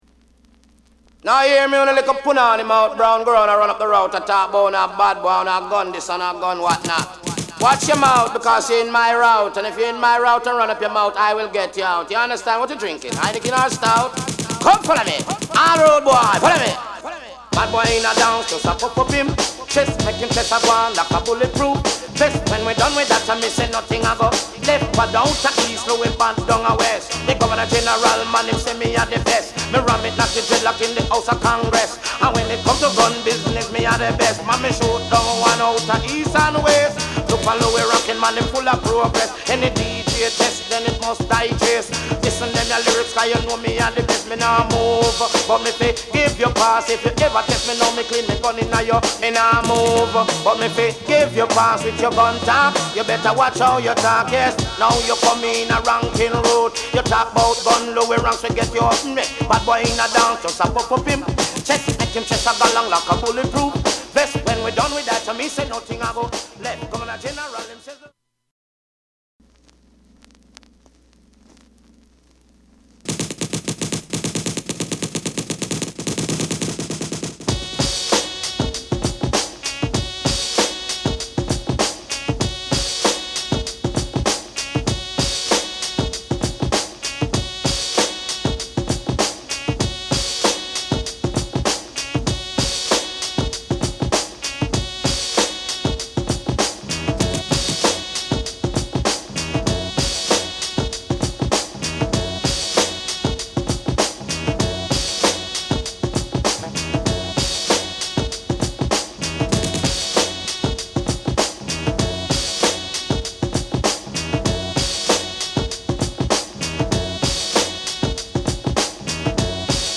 HIP HOP MIX 2.